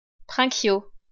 Prinquiau (French pronunciation: [pʁɛ̃kjo]